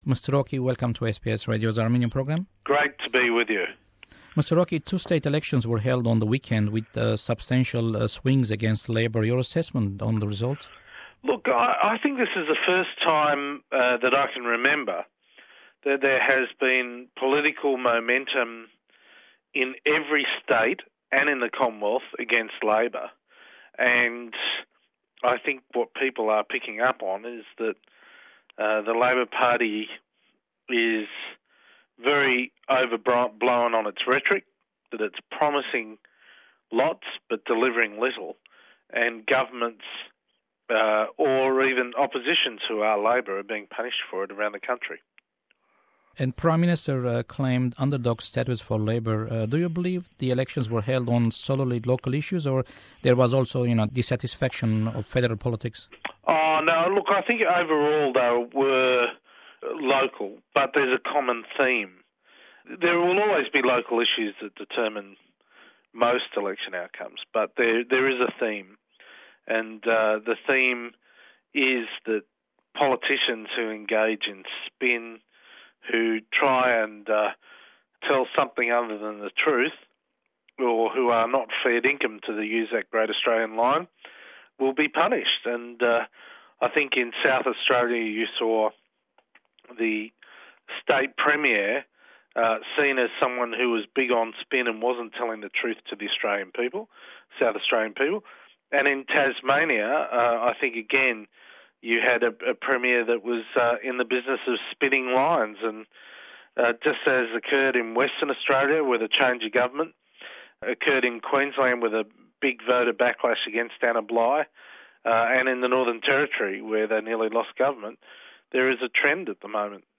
Celebrating 40 years of SBS Armenian. This week we revisit 2010 with an interview broadcast in March of that year with the former federal treasurer and current Australian Ambassador to the US Joe Hockey.